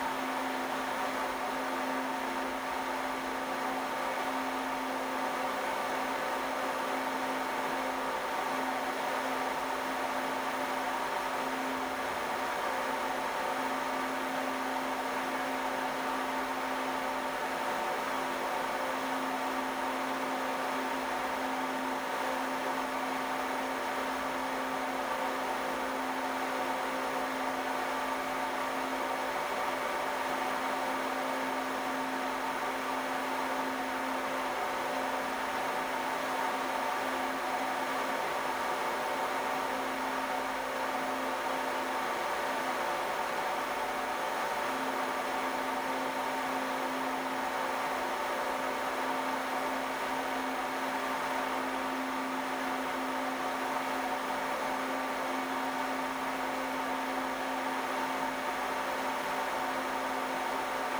There is a noise spike in the 250-350Hz region.
I have recorded the signals shown above, but please keep in mind that I’ve enabled Automatic Gain Control (AGC) to do so to make it easier for you to reproduce them.
50% Fan Speed
XPG-Nidec-Vento-Pro-120-PWM-50-Fan-Speed.mp3